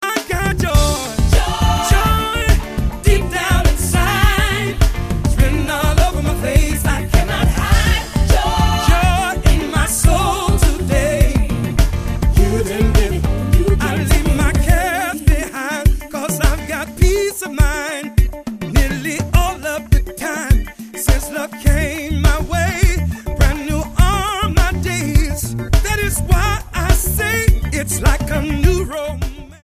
STYLE: Gospel
stomps along nicely